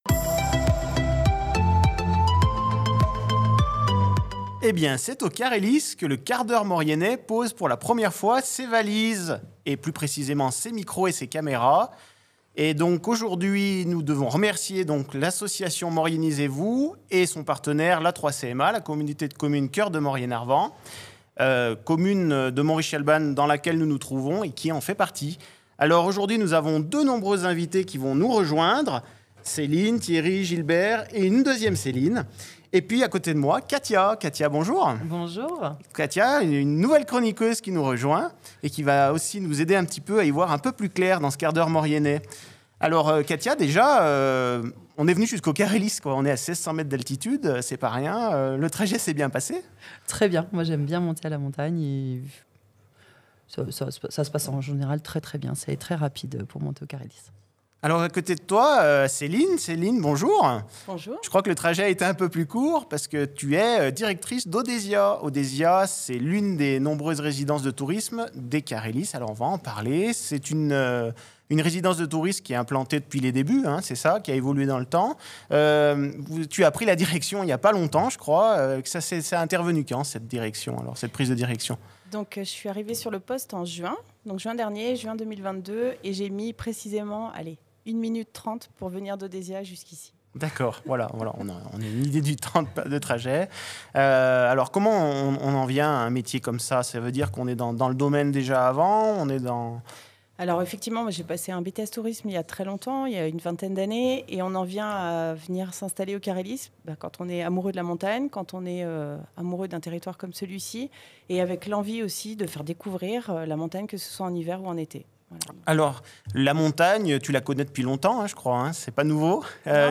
Dans cet épisode, nous avons l'honneur de recevoir deux invitées exceptionnelles.